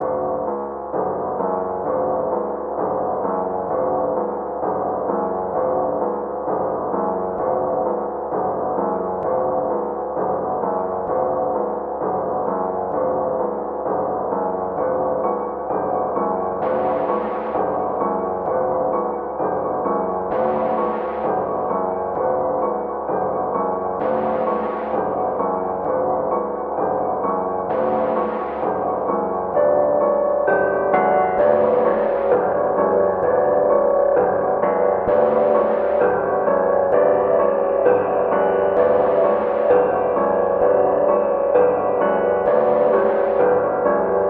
SOUTHSIDE_melody_loop_scheme_130_Bm.wav